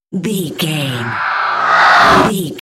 Creature riser horror
Sound Effects
In-crescendo
Atonal
scary
ominous
haunting
eerie
roar